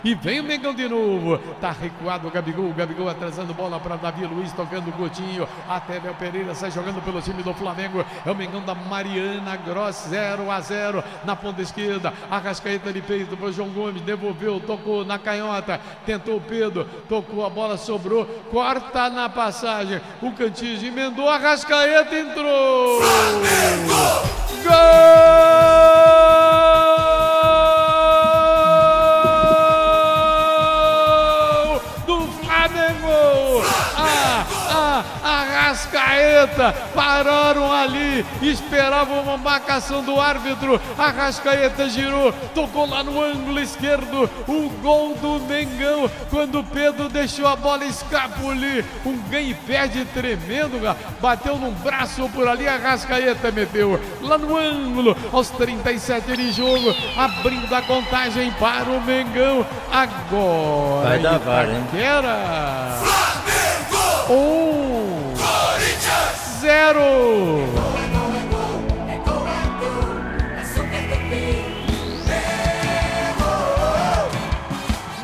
Ouça os gols da vitória do Flamengo sobre o Corinthians na Libertadores com a narração do Garotinho